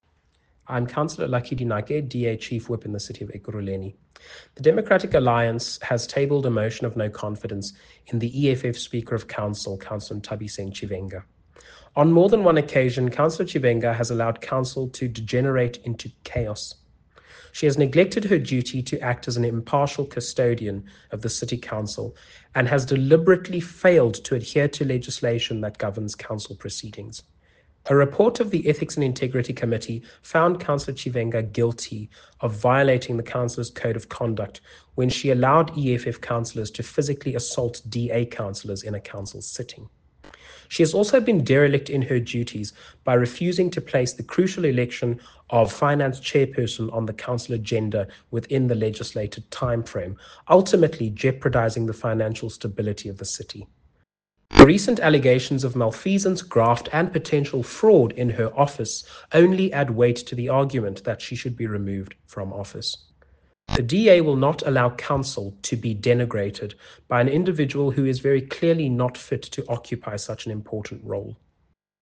Note to Editors: Please find an English soundbite by Cllr Lucky Dinake